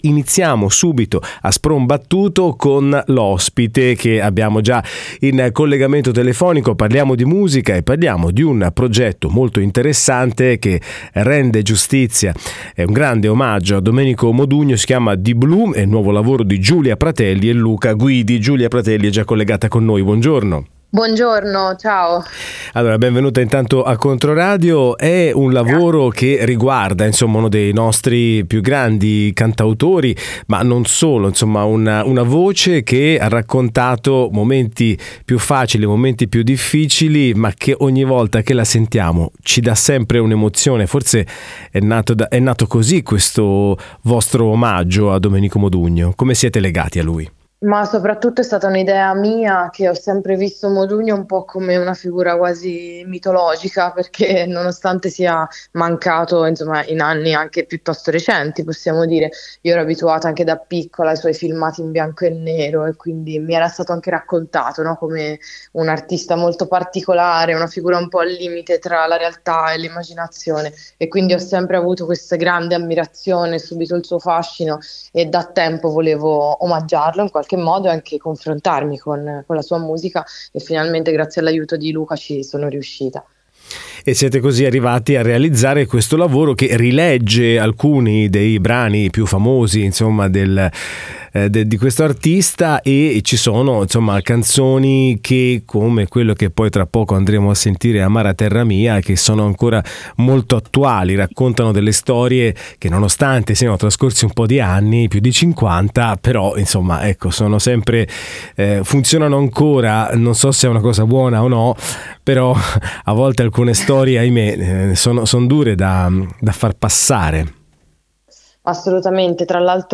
L'intervista.